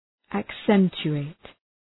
Προφορά
{æk’sentʃʋ,eıt} (Ρήμα) ● τονίζω